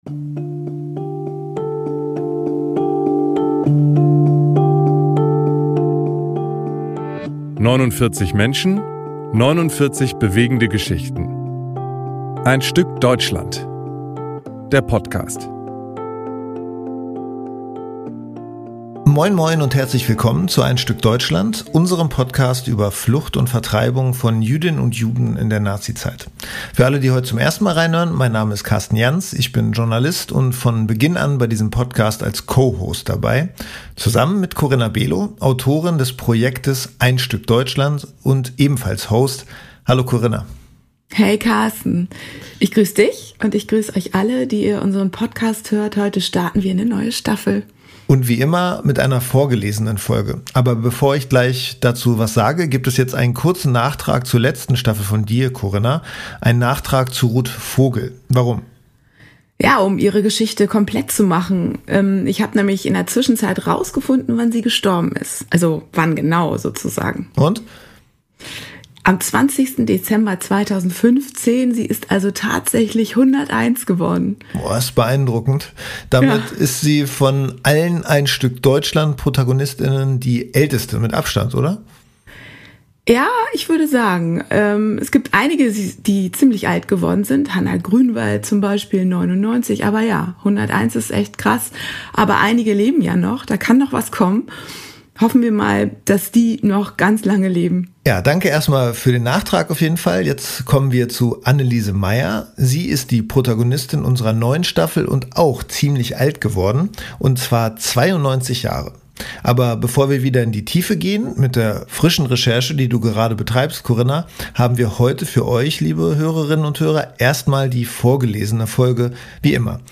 die Schauspielerin Lena Klenke uns ihre Geschichte vorliest.